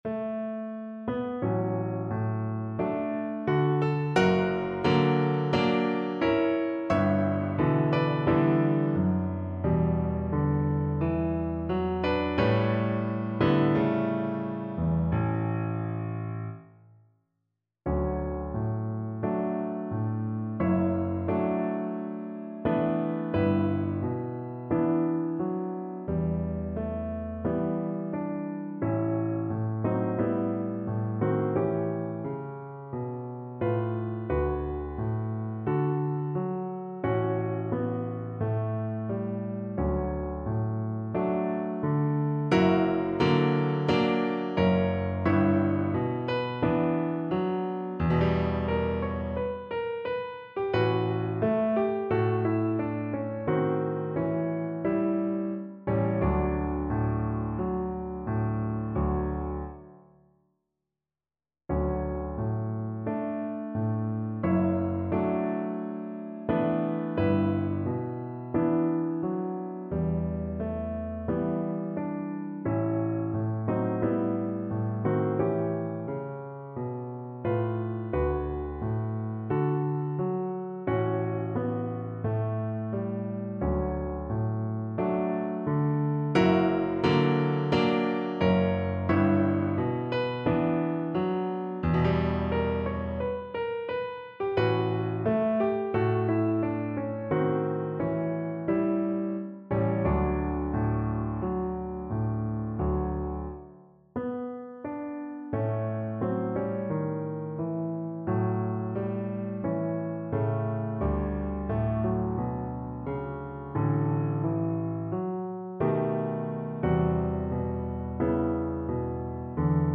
Andante =60
2/4 (View more 2/4 Music)
Classical (View more Classical Violin Music)